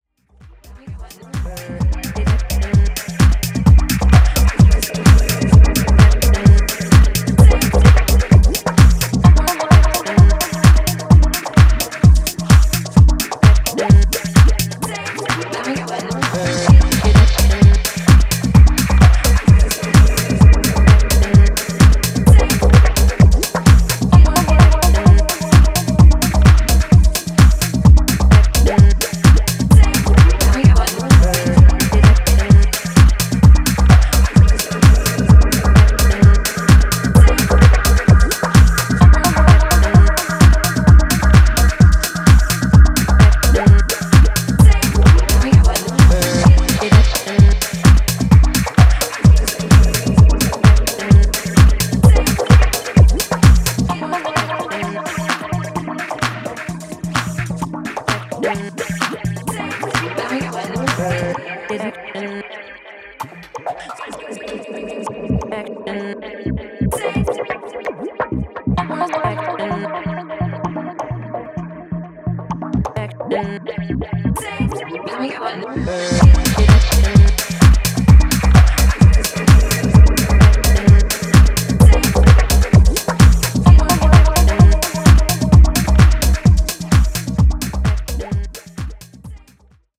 チョップド・ヴォーカルとダビーな音響でやんわり煽るハウス・ローラー